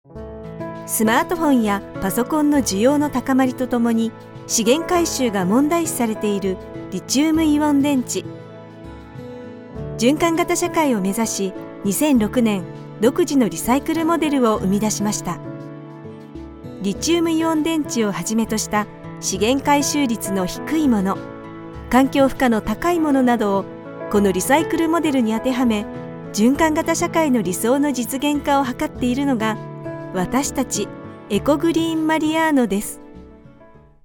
Comercial, Natural, Seguro, Cálida, Empresarial
Corporativo
personable, persuasive, versatile, warm and authentic